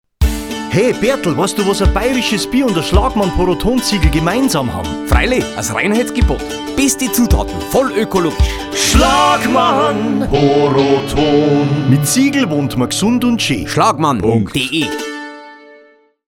Radiowerbung / Telefonschleifen